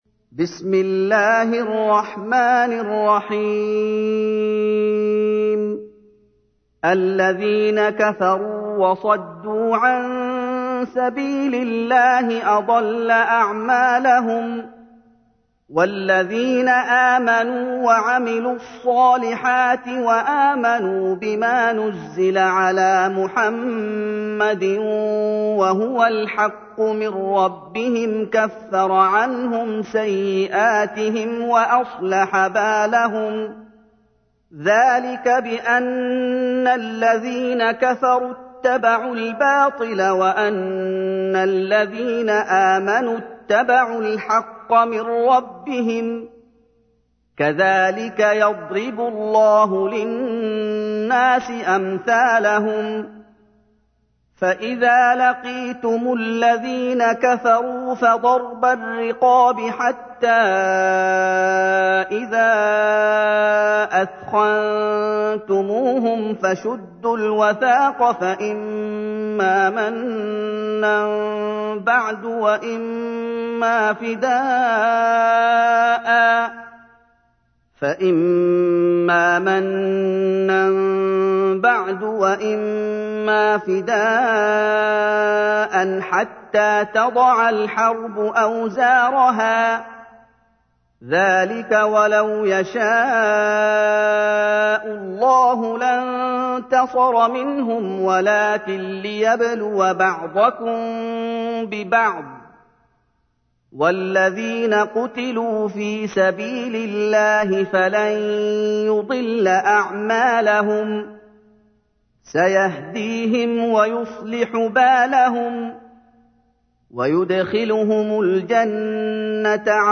تحميل : 47. سورة محمد / القارئ محمد أيوب / القرآن الكريم / موقع يا حسين